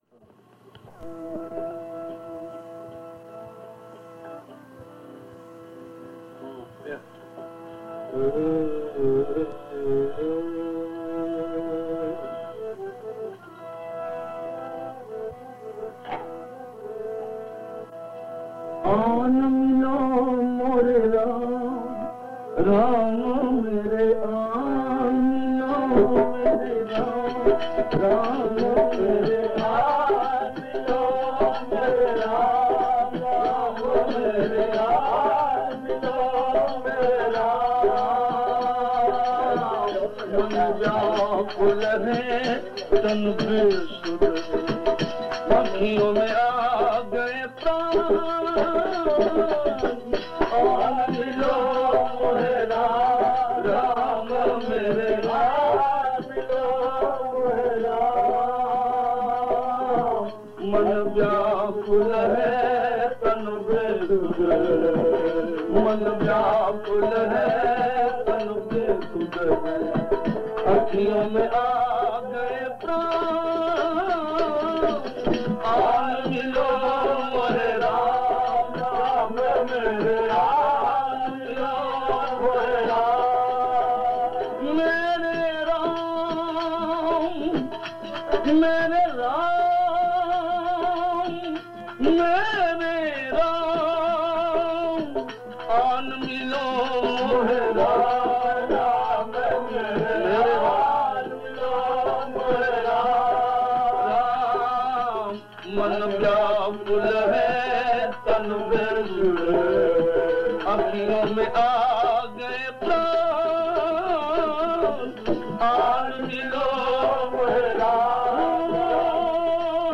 Bhajans
Jul 1994, cassette, side-b